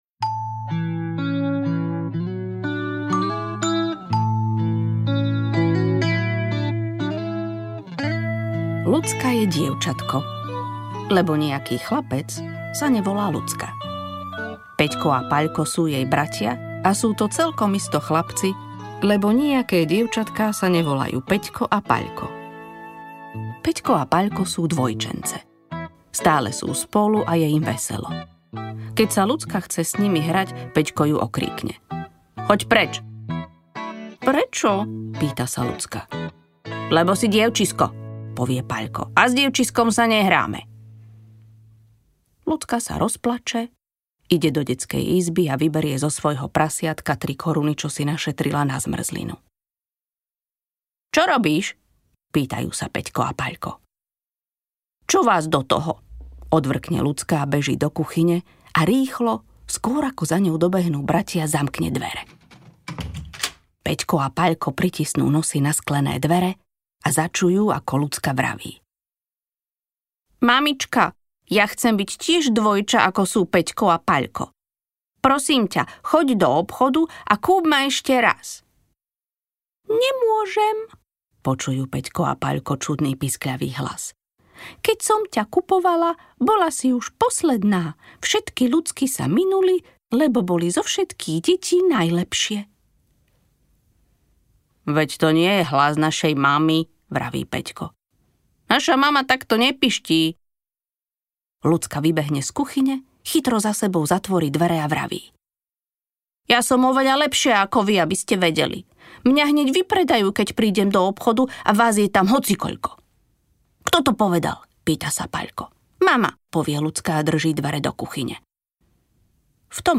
S dievčiskom sa nehráme audiokniha
Ukázka z knihy
Nahraté v štúdiu PATT Production v Košiciach v októbri a novembri 2025